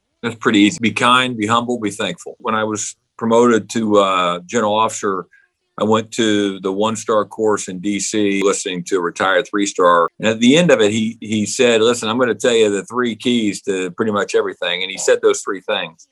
Former and current commanders of Fort Riley joined a virtual panel to discuss leadership both in and out of the military.
Current Commanding General D.A. Sims was asked what advice he would give to the future leaders.